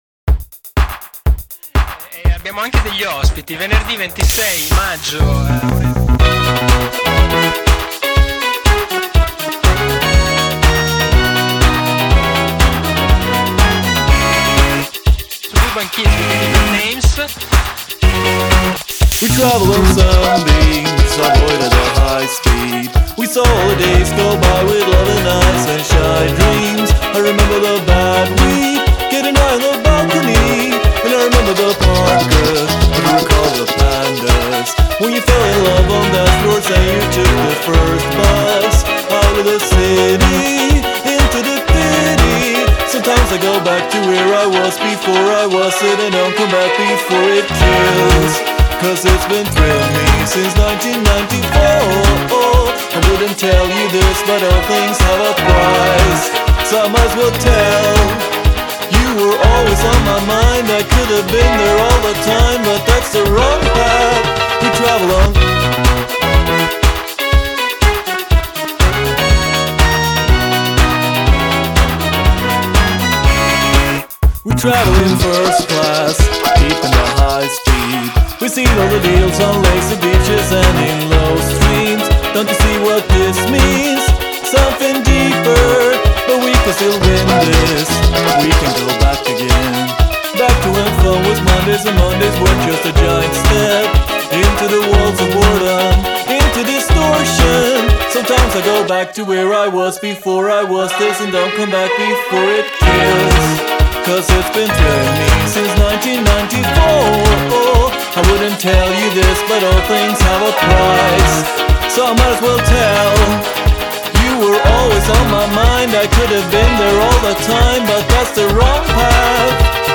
La canzone ha un curioso passo quasi disco "da crociera"